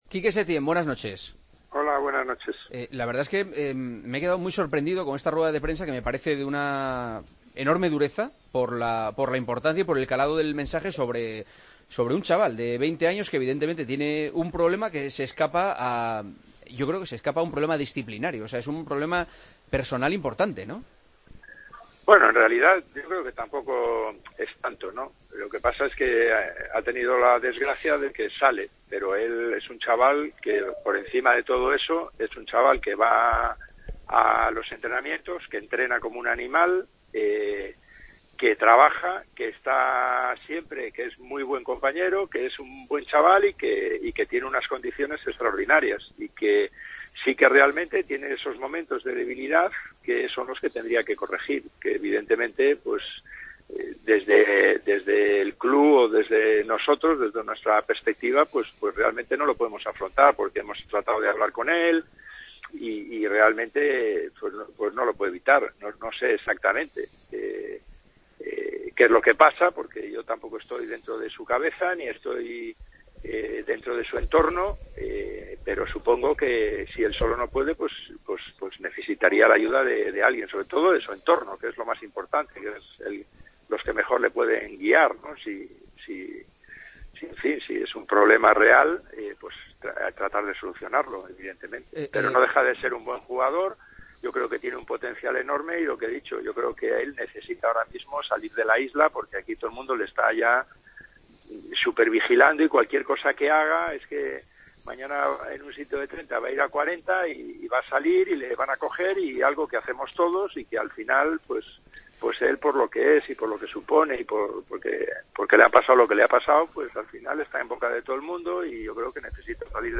El Partidazo de Cope llamó al entrenador de la UD Las Palmas, Quique Setién, para profundizar en la situación de Araujo: "Es un buen chaval, tiene esos momentos de debilidad que tendría que corregir.